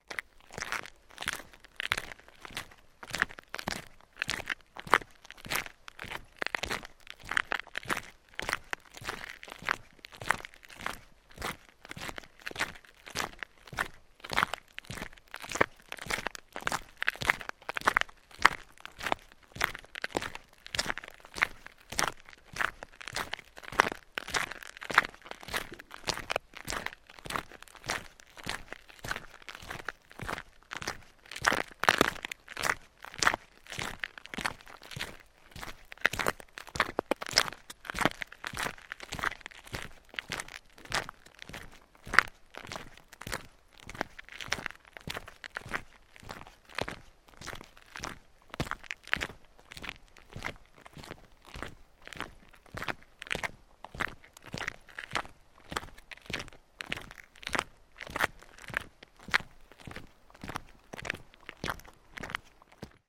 Звуки шагов по земле
На этой странице собрана коллекция реалистичных звуков шагов по различным типам земной поверхности.